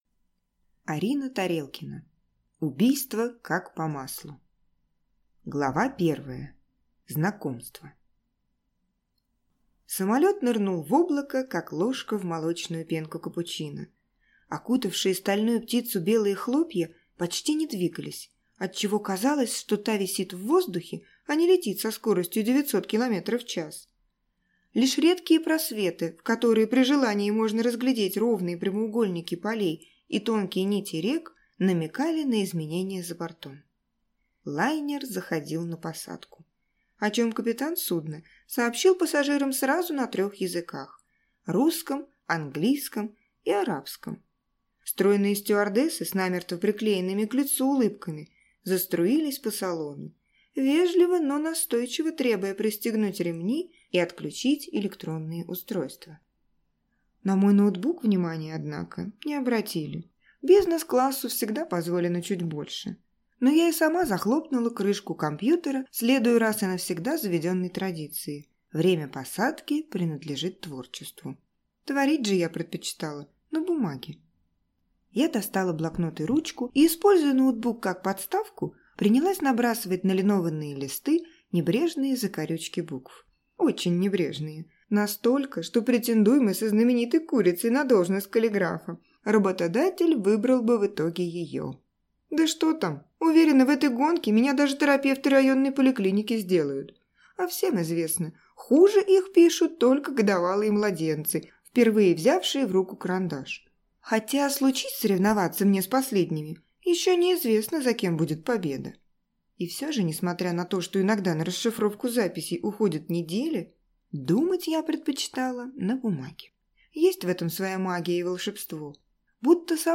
Аудиокнига Убийство как по маслу | Библиотека аудиокниг